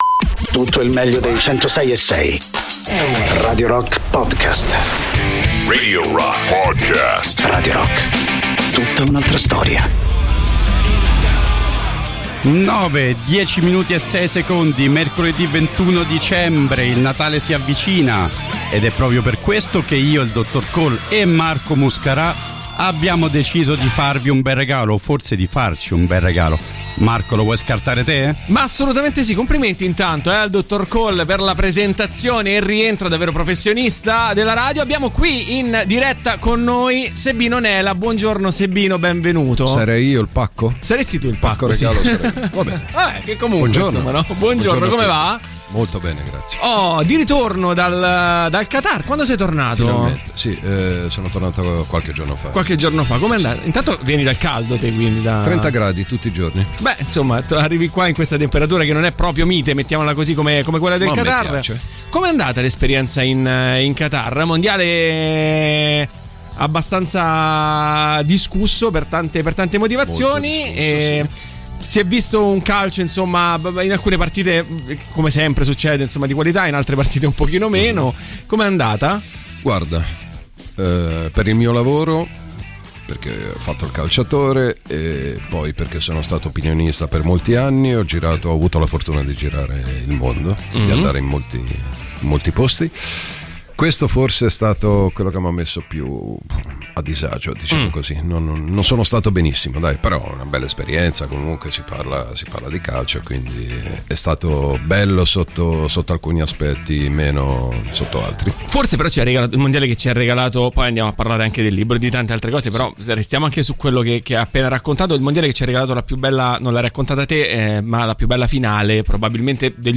Interviste: Sebino Nela (21-12-22)